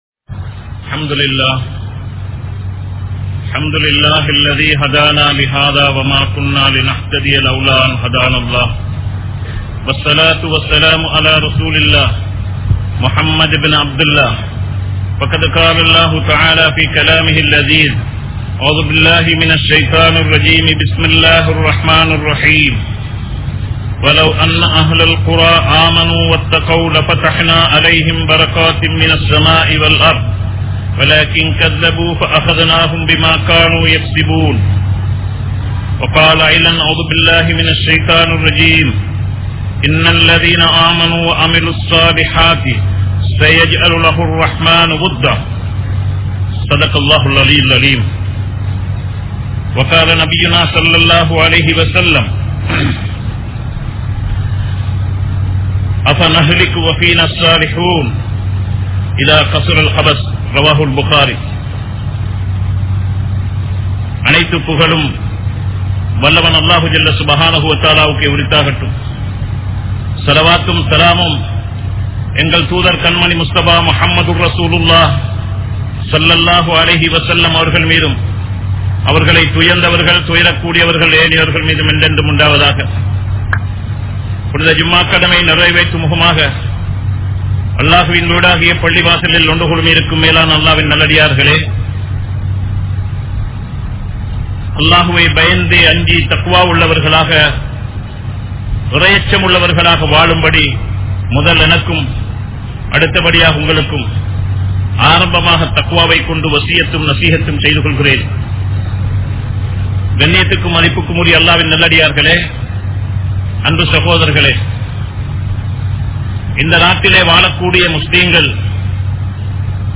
Mun Maathiriyaana Muslimkal (முன் மாதிரியான முஸ்லிம்கள்) | Audio Bayans | All Ceylon Muslim Youth Community | Addalaichenai
Colombo 03, Kollupitty Jumua Masjith